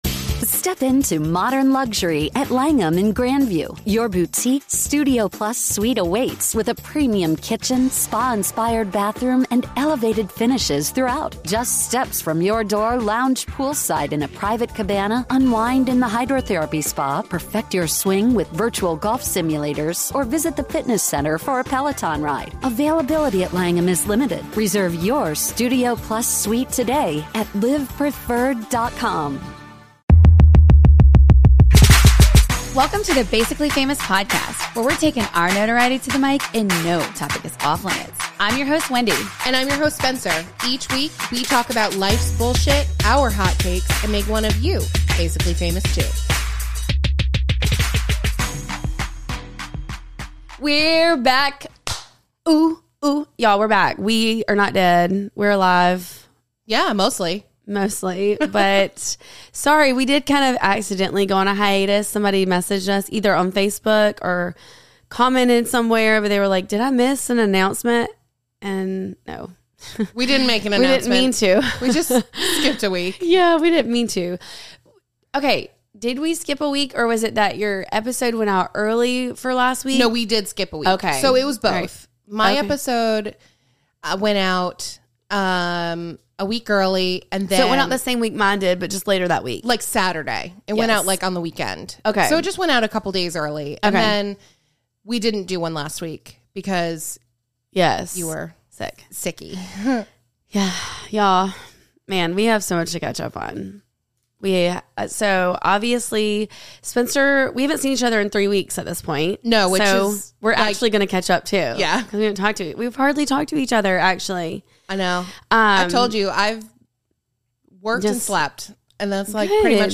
Why does it feel like we were on hiatus for a month? We're back in studio today catching up on our trips, life in general, Love is Blind and most importantly, giving some advice to a caller who's in a bridesmaid conundrum.